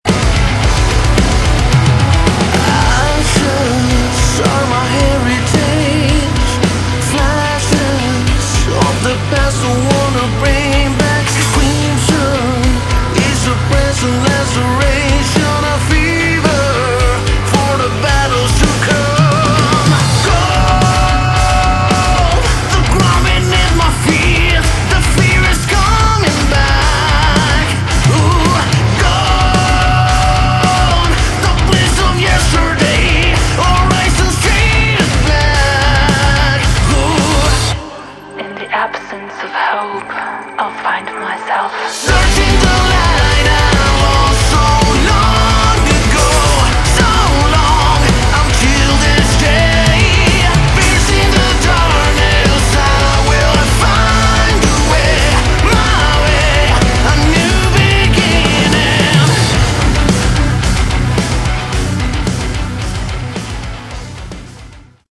Category: Melodic Metal
vocals, guitars, bass, piano, programming
drums
backing vocals